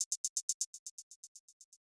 TrappyHats.wav